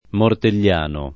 [ mortel’l’ # no ]